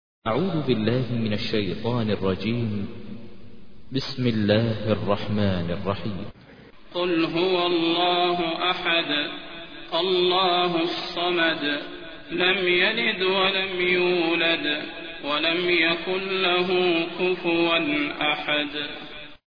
تحميل : 112. سورة الإخلاص / القارئ ماهر المعيقلي / القرآن الكريم / موقع يا حسين